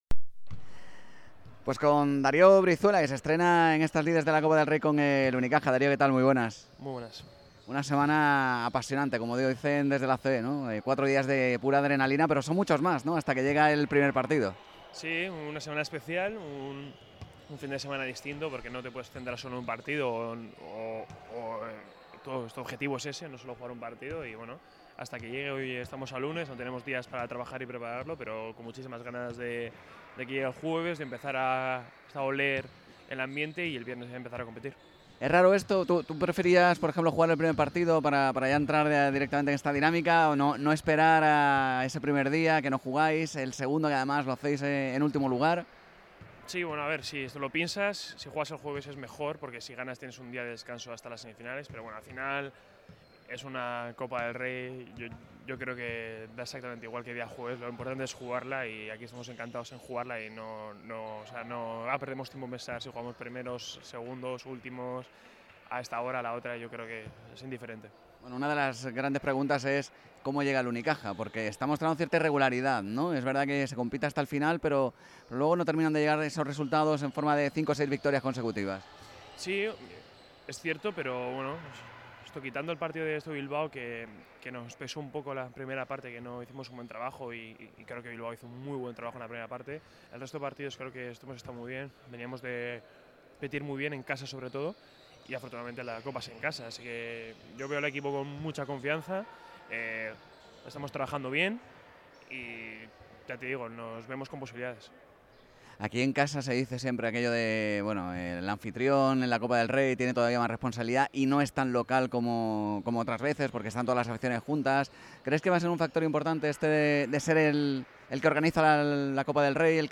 Entrevista a Darío Brizuela en Radio Marca Málaga